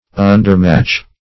Undermatch \Un"der*match`\, n.